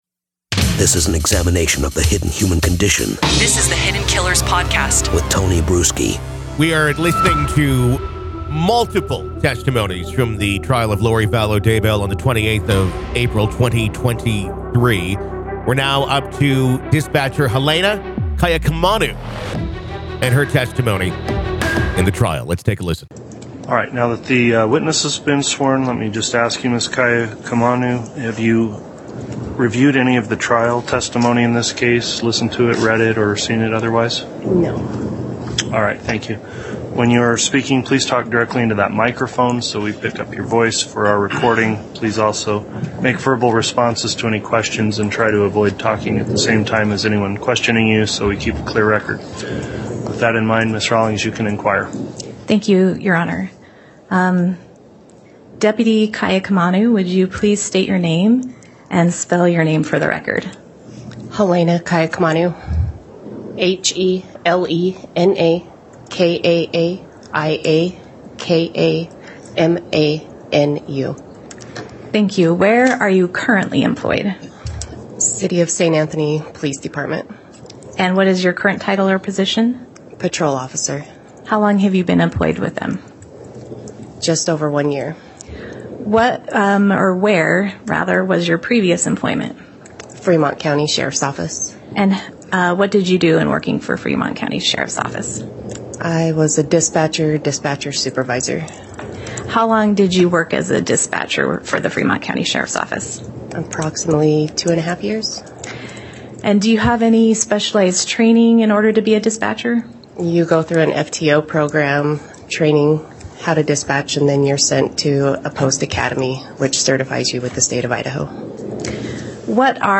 The Trial Of Lori Vallow Daybell | Full Courtroom Coverage